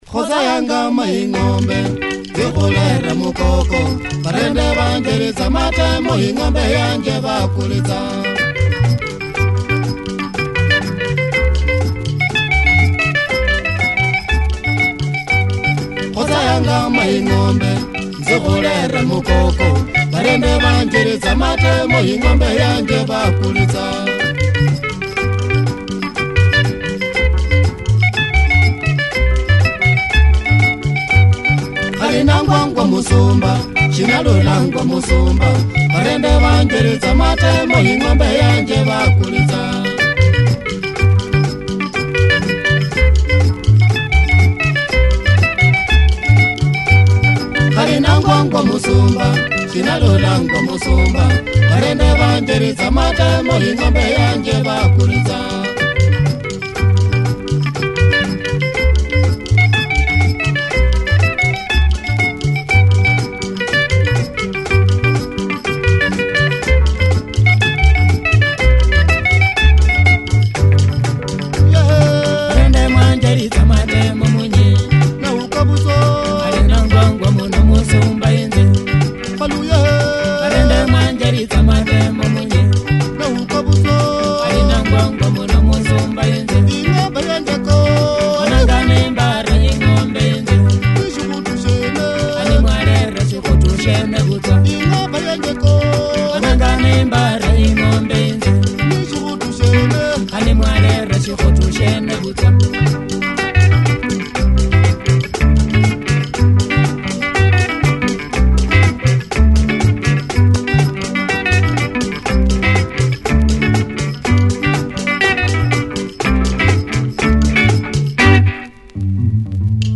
Nice chunky luhya benga, check audio of boths sides! https